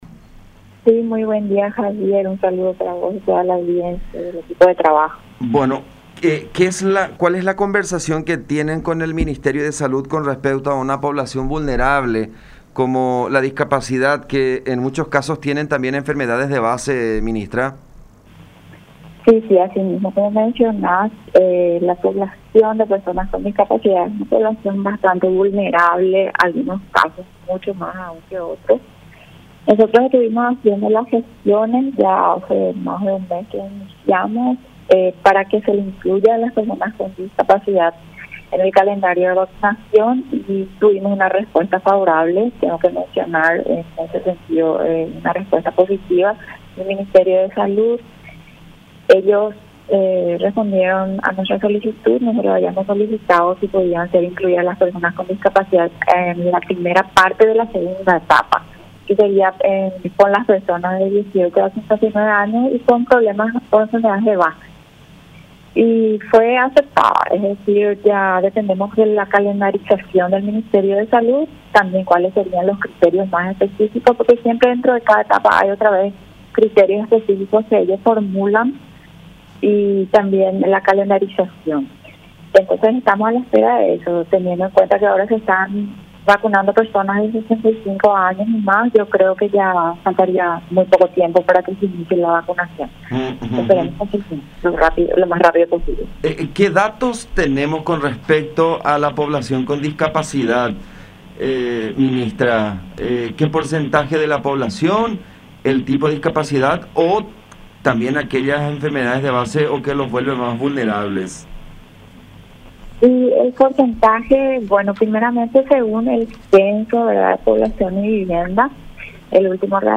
“Ellos respondieron a nuestra solicitud, nosotros habíamos solicitado si podían ser incluidas las personas con discapacidad en la primera parte de la segunda etapa, que sería con las personas de 18 a 59 años con problemas o enfermedades de base, y fue aceptada, es decir ya dependemos de la calendarización del Ministerio de Salud, también cuales serían los criterios más específicos, porque siempre dentro de cada etapa que ellos formulan” señaló la ministra de la SENADIS, Mariela Ramírez, en contacto con La Unión R800 AM